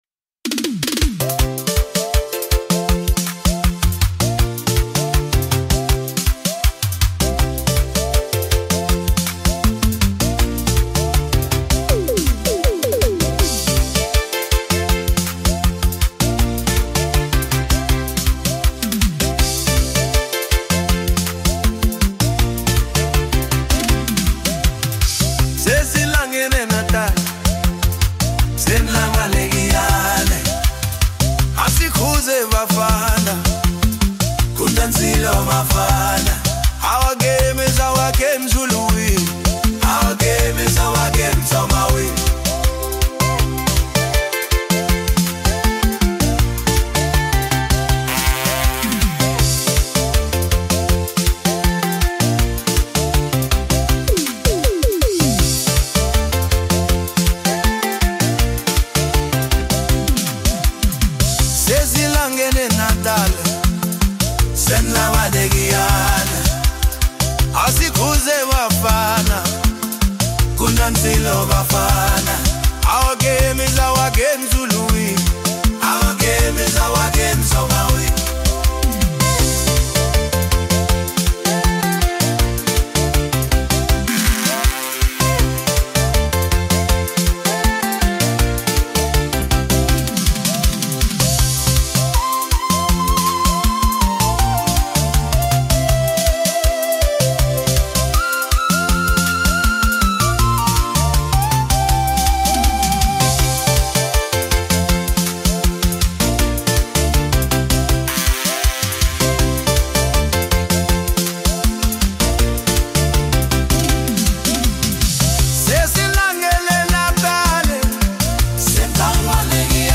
Home » Deep House » Gqom » Hip Hop